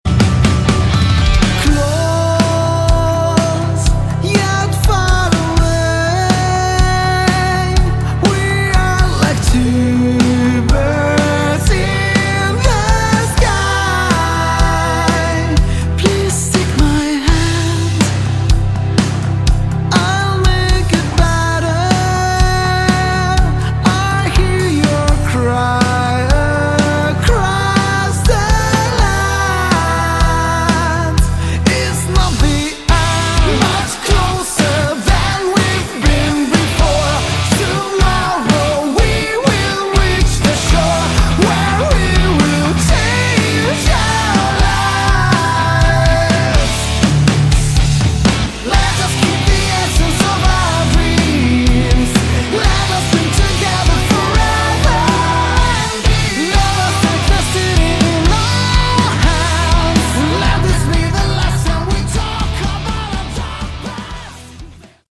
Category: Melodic Rock
vocals
guitar
bass
keyboard
drums